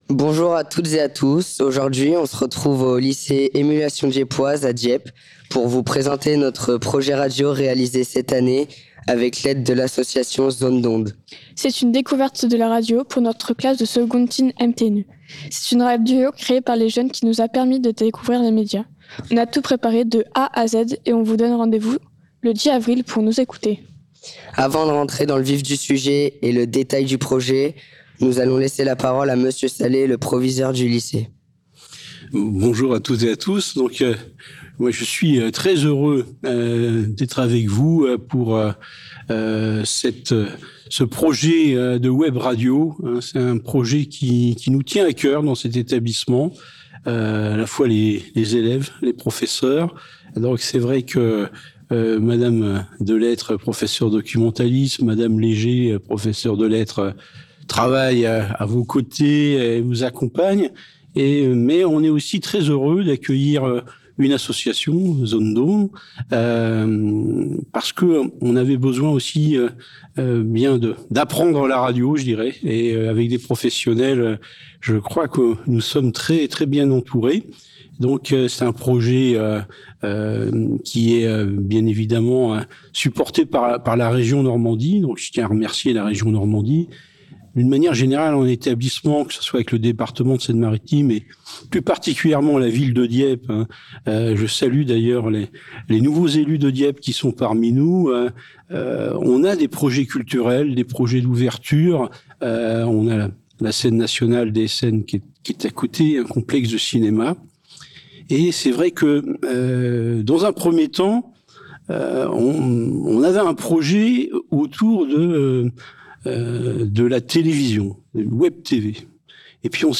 Conférence de Presse le 23-03-26